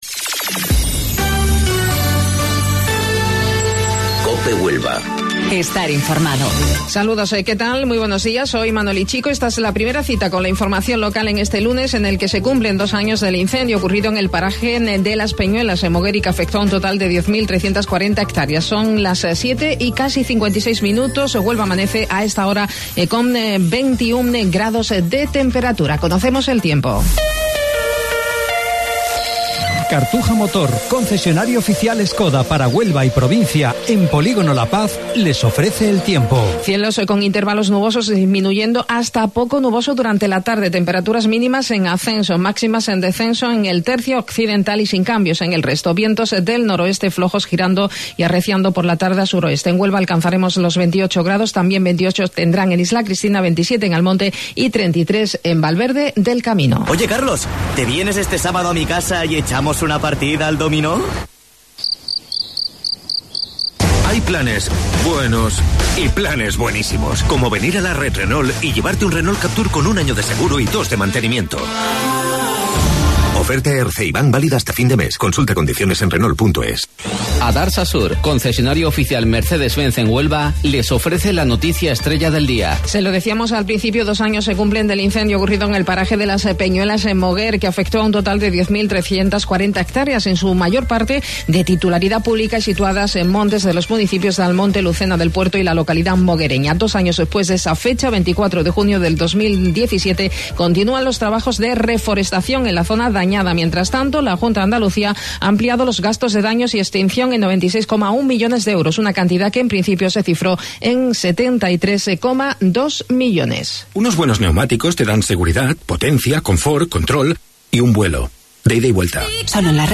AUDIO: Informativo Local 07:55 del 24 de Junio